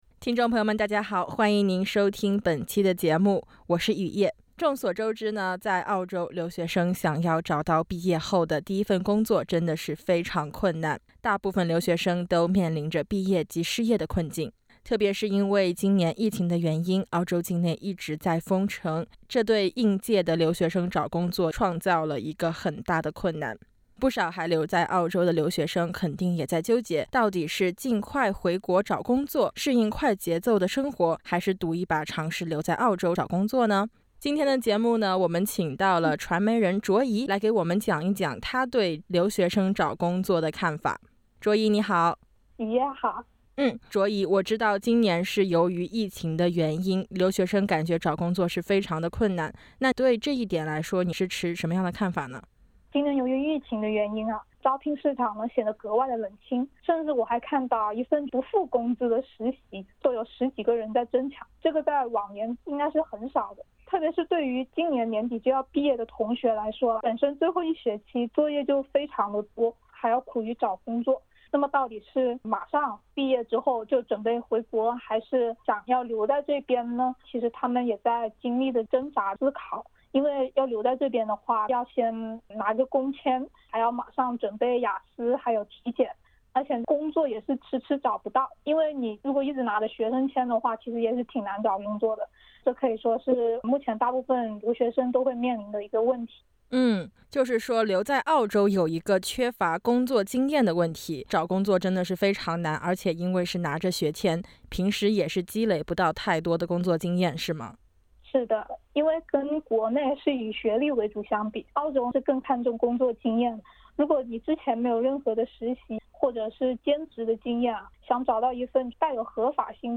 到底是留是去，疫情下的澳洲留學生面臨著兩難的境地。 請點擊封面，收聽完整寀訪。